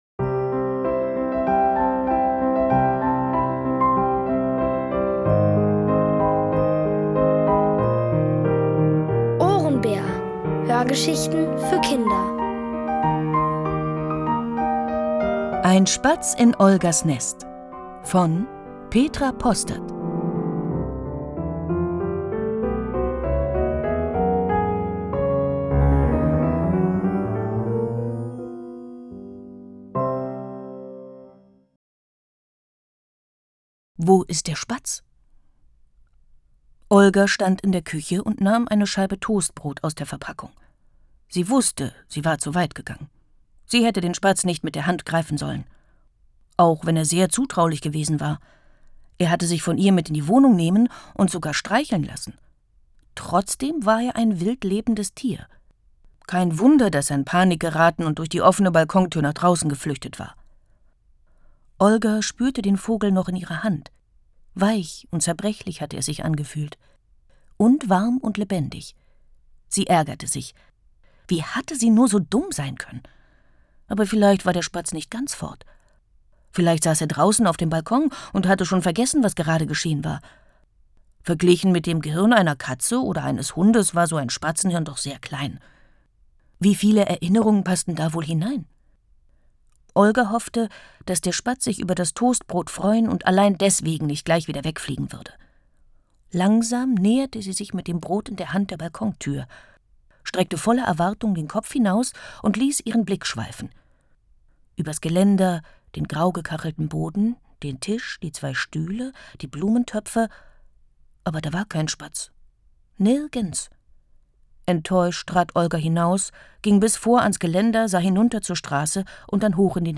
Von Autoren extra für die Reihe geschrieben und von bekannten Schauspielern gelesen.
Kinder & Familie